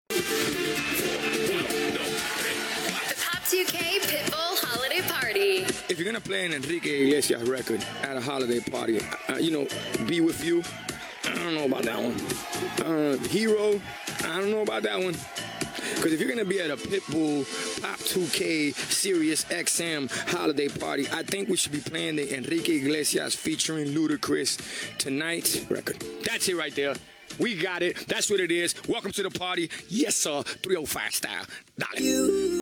Pitbull took over SiriusXM’s Pop 2K for a holiday party take-over today.